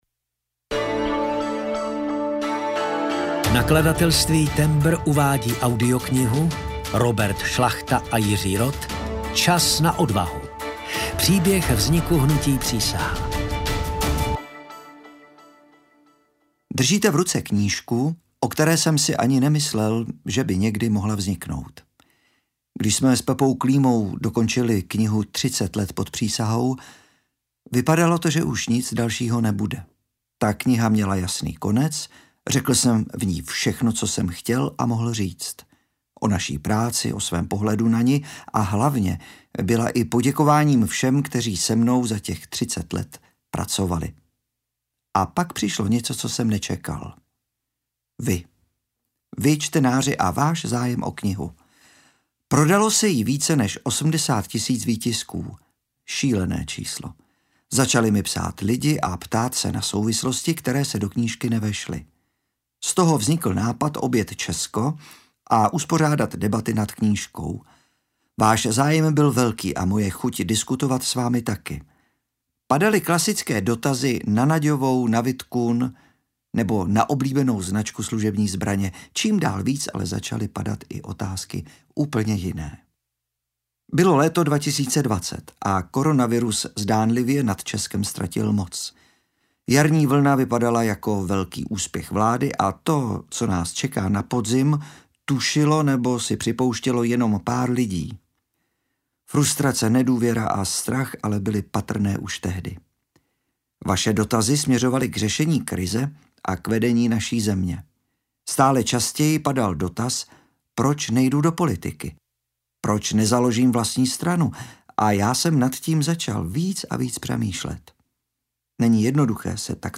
Čas na odvahu – Příběh vzniku hnutí Přísaha audiokniha
Ukázka z knihy